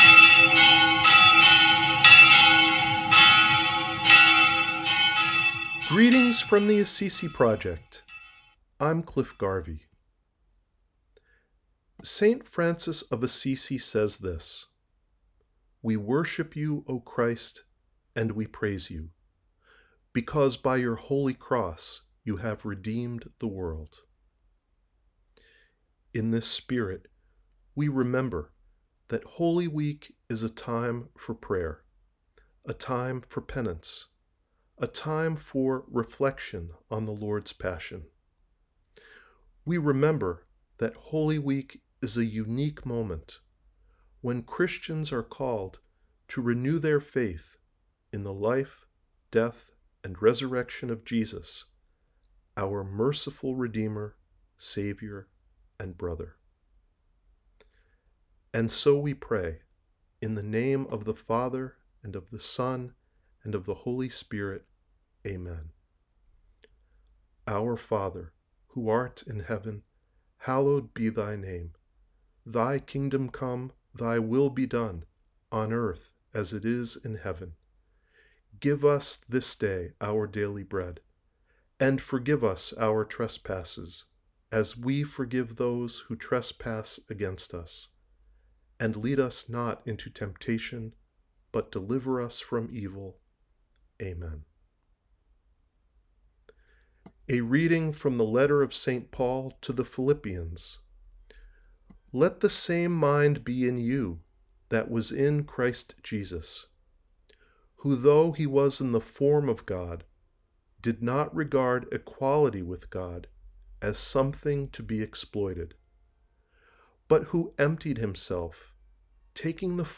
cropped-pieta.jpgA PRAYER FOR HOLY WEEK